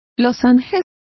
Complete with pronunciation of the translation of lozenges.